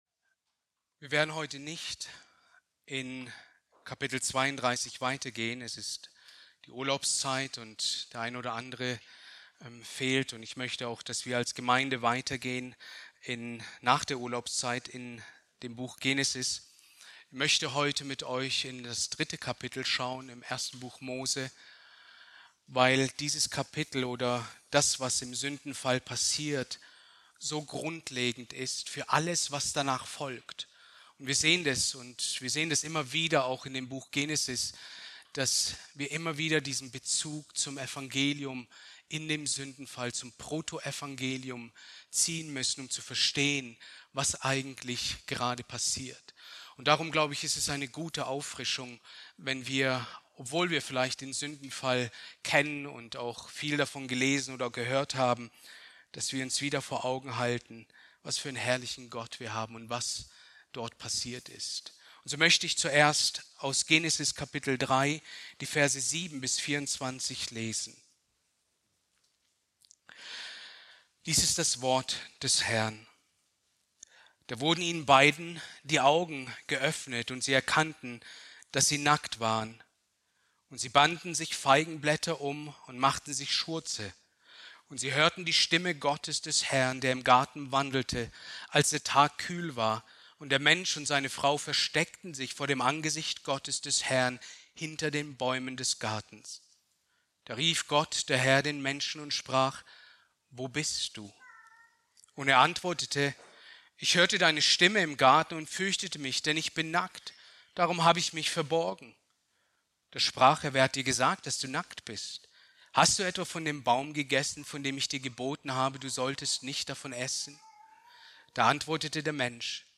Predigt aus der Serie: "Genesis"